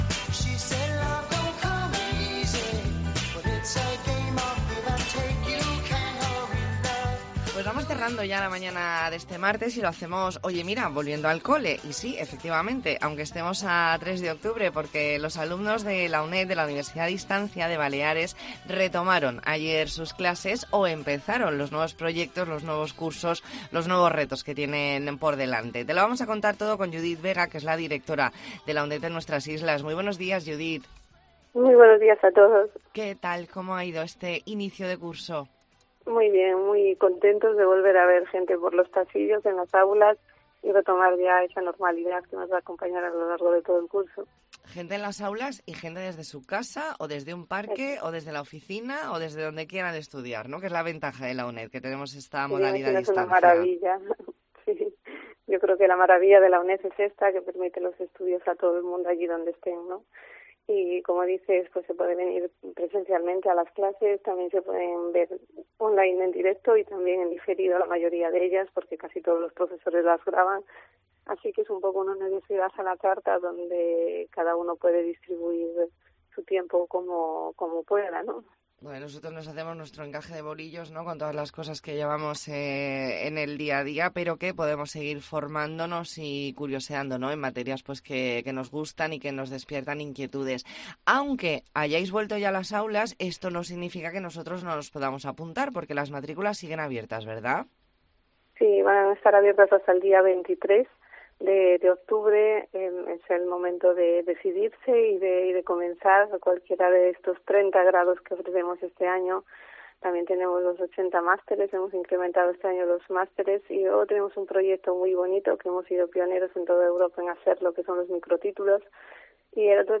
Entrevista en La Mañana en COPE Más Mallorca, martes 3 de octubre de 2023.